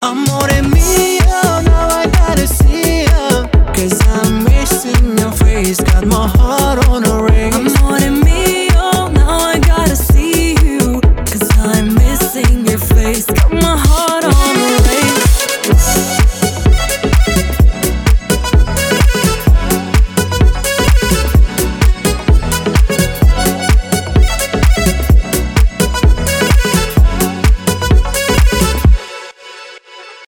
dance pop
поп , аккордеон